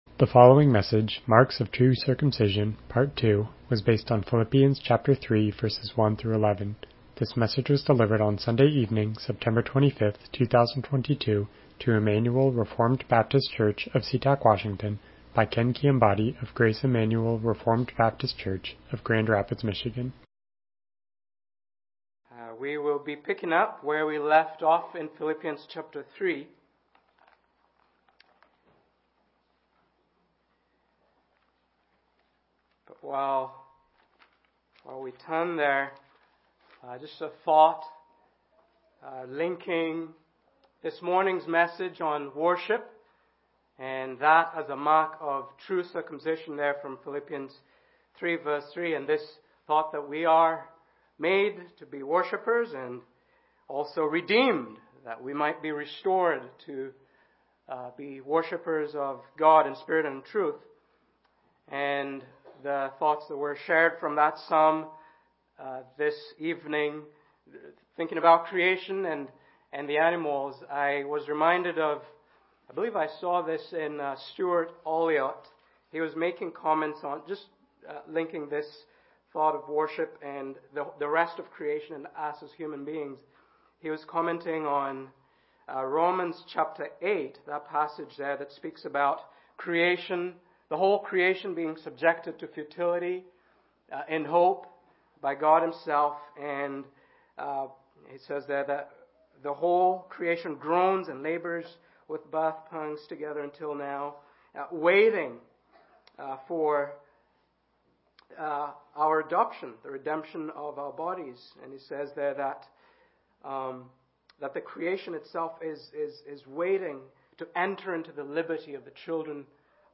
Passage: Philippians 3:1-11 Service Type: Evening Worship « Marks of True Circumcision